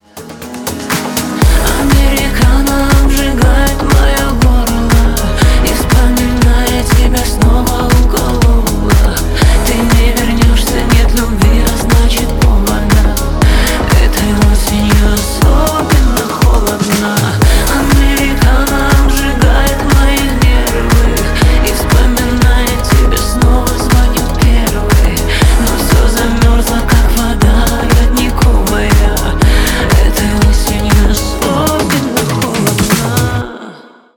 поп , ремиксы